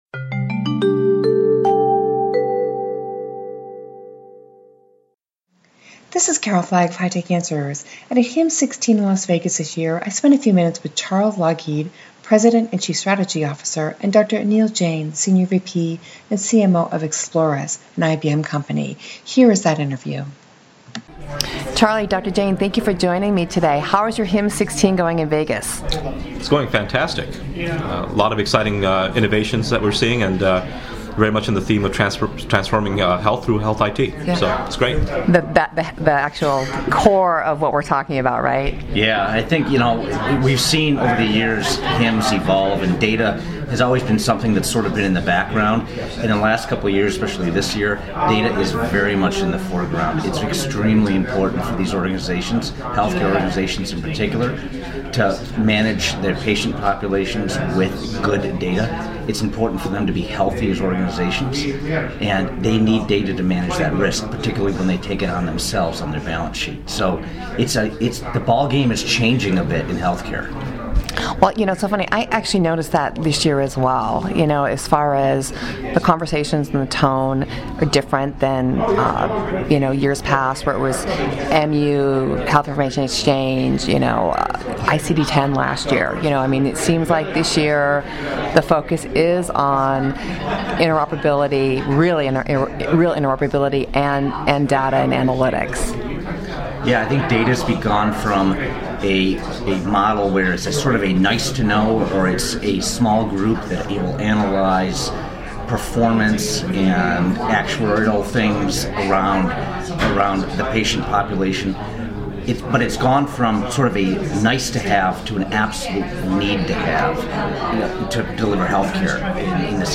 At HIMSS16 in Las Vegas the conversation was dominated by three topics - interoperability, cybersecurity and the shift to value-based care. I got the chance to interview industry leaders and vendors on these topics and associated provider challenges.